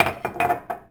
household
Coffee Cup Drop 3